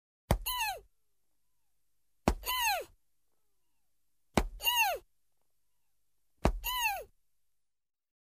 На этой странице собраны веселые и яркие звуки мультяшных драк: звонкие удары, шлепки, взвизгивания и другие забавные эффекты.
Удары и стон